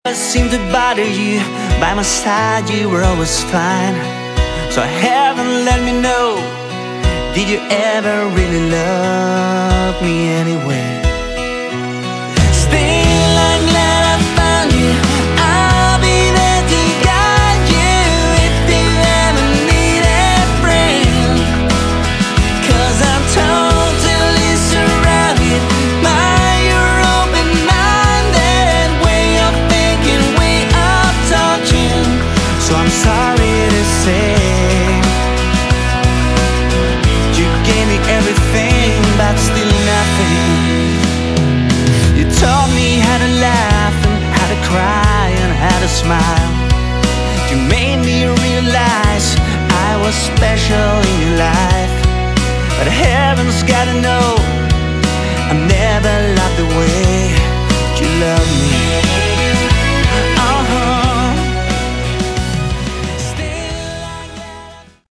subtle Hard Rock/AOR album
genuine and original Melodic hard rock with AOR flavour